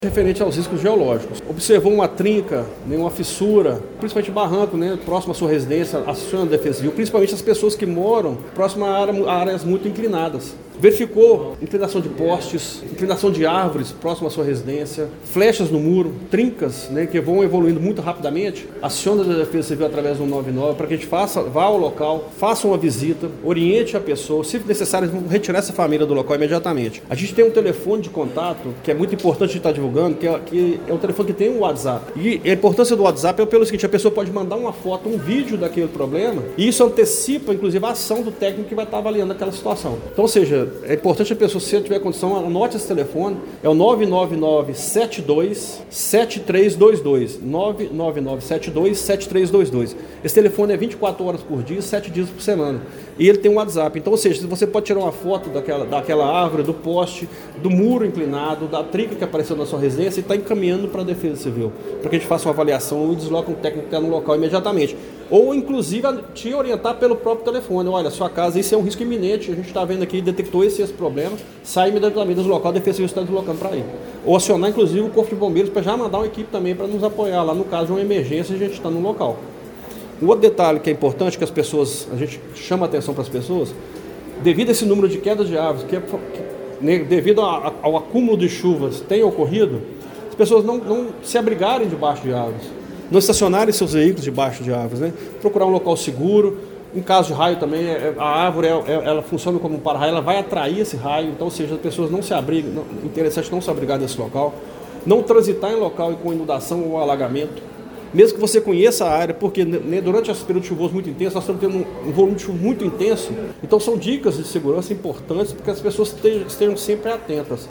O coordenador de Proteção e Defesa Civil, Edson Cecílio da Silva, apresentou uma análise detalhada do cenário climático esperado para os próximos dias em Pará de Minas, durante coletiva de imprensa realizada na tarde de ontem (24).